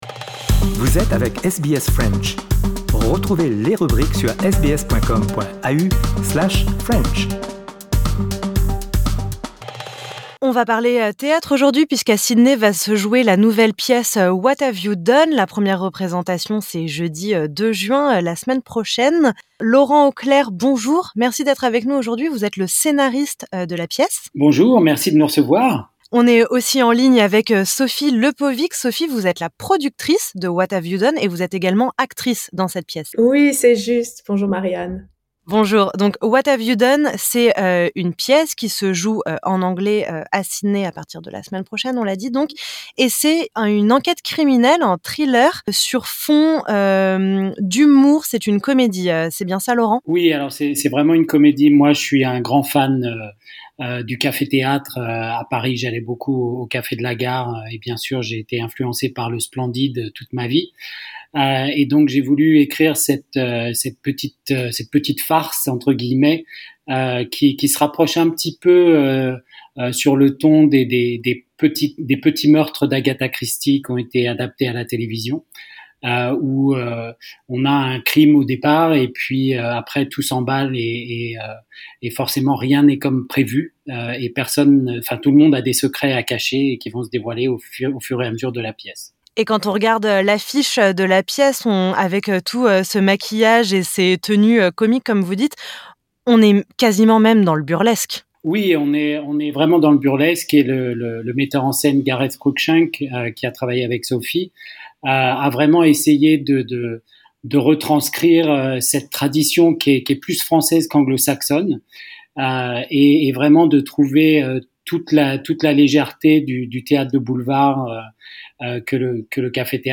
Entretien.